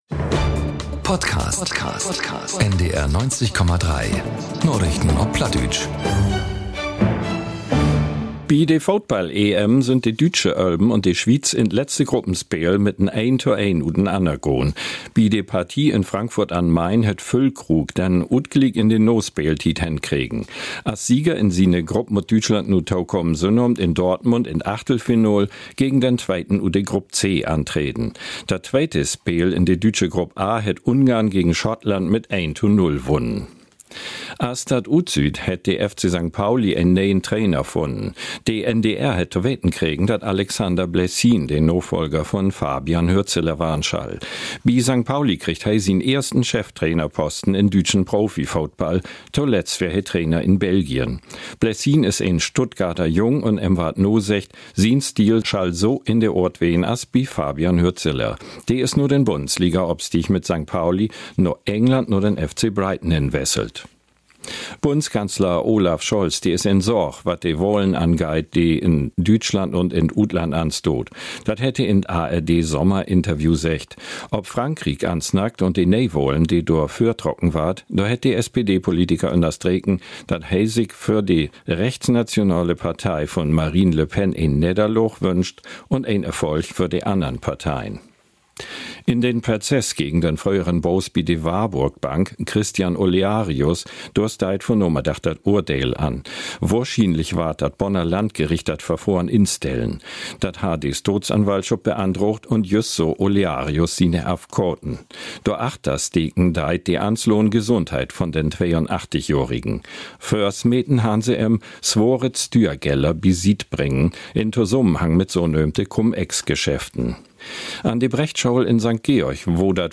Nachrichten - 24.06.2024